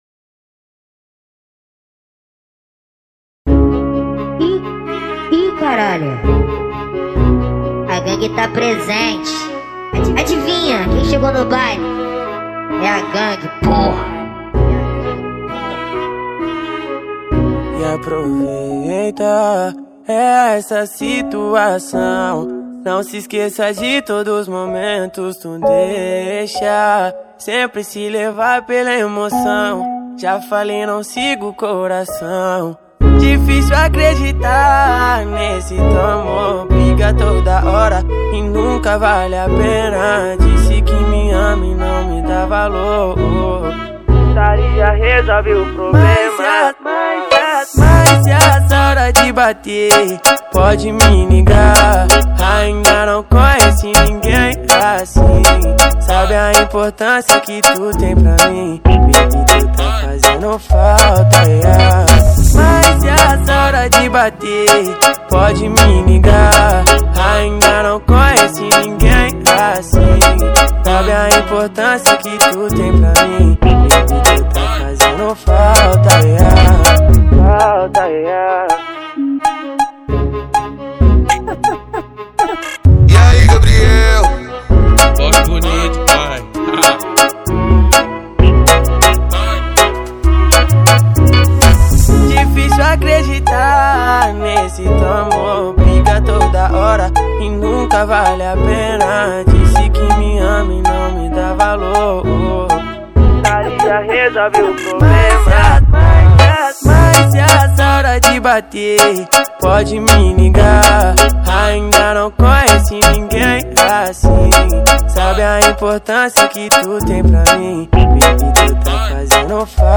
2024-03-05 20:31:53 Gênero: Funk Views